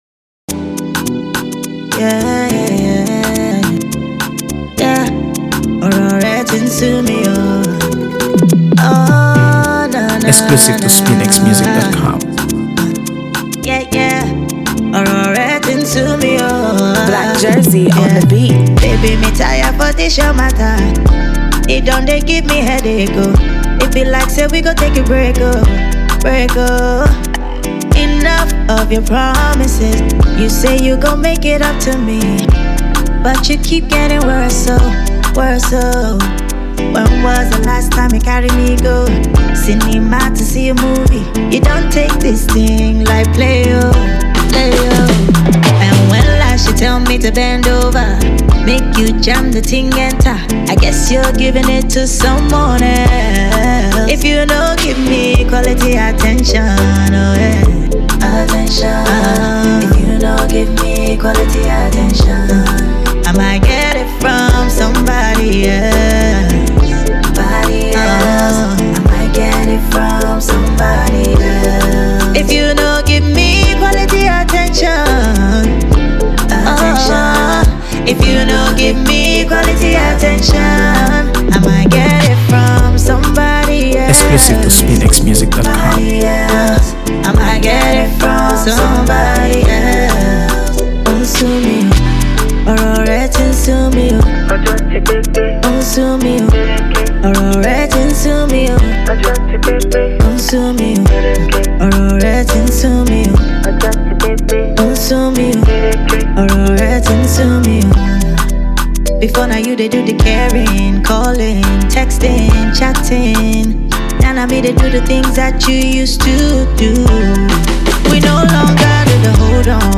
AfroBeats | AfroBeats songs
delivers a smooth, captivating blend of rhythm and emotion